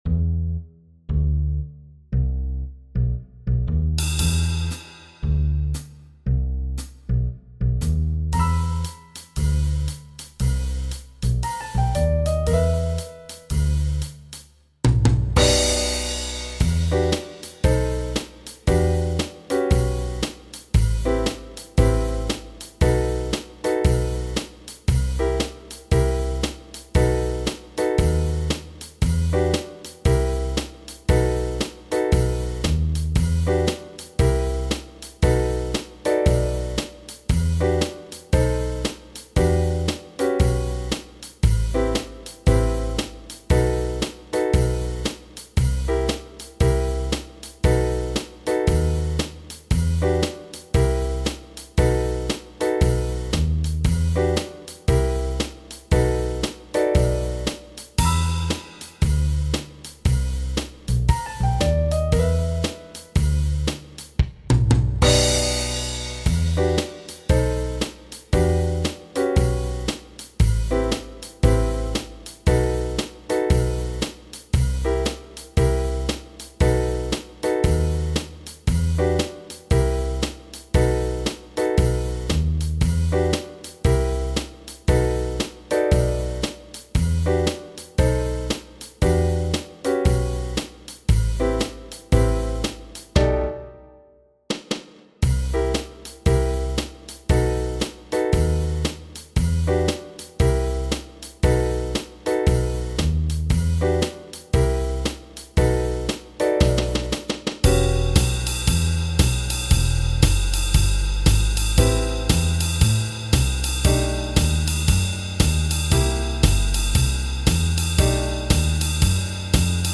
Runterladen (Mit rechter Maustaste anklicken, Menübefehl auswählen)   Die Freiheit (Playback)
Die_Freiheit__4_Playback.mp3